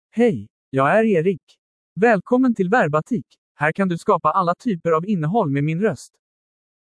EricMale Swedish AI voice
Eric is a male AI voice for Swedish (Sweden).
Voice sample
Listen to Eric's male Swedish voice.
Male
Eric delivers clear pronunciation with authentic Sweden Swedish intonation, making your content sound professionally produced.